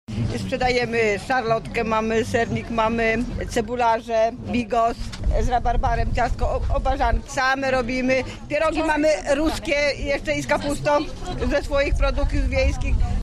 • mówią panie z Koła Gospodyń „Nowiny”.